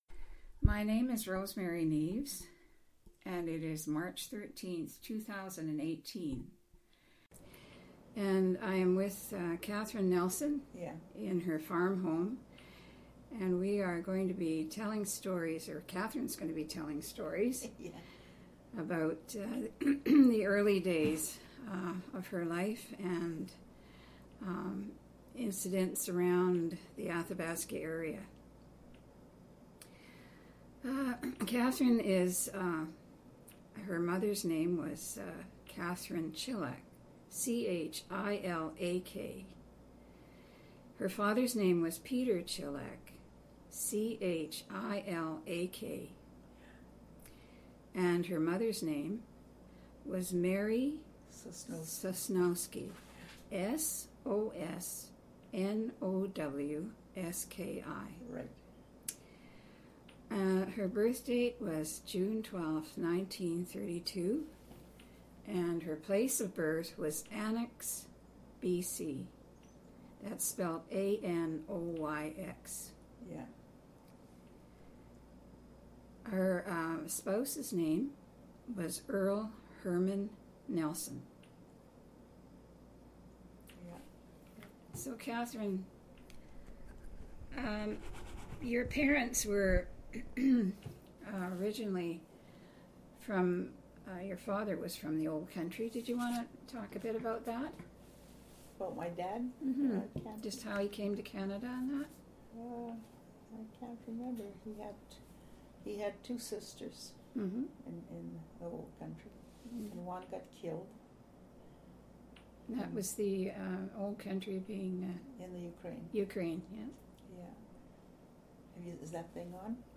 Audio interview, transcript of audio interview and obituary,